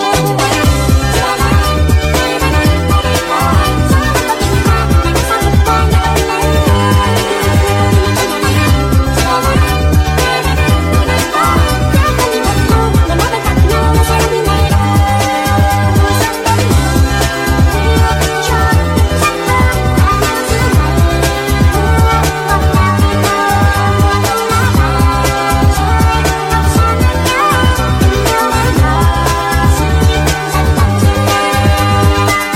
Speed Up